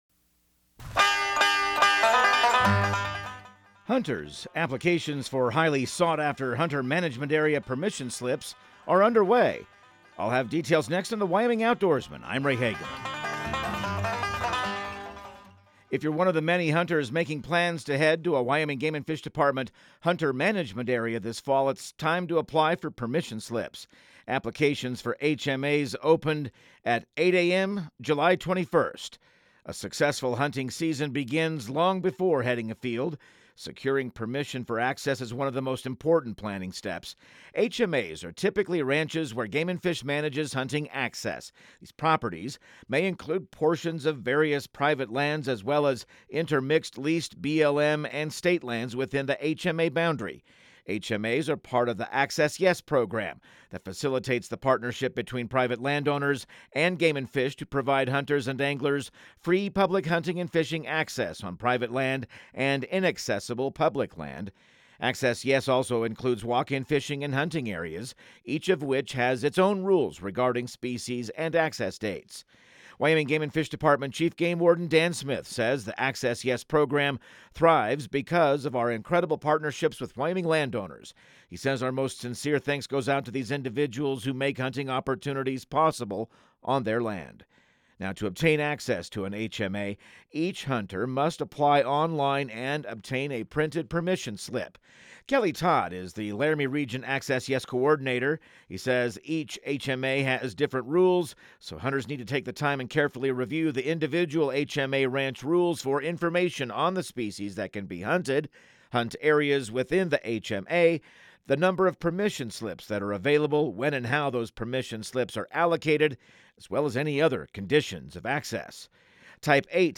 Radio news | Week of July 21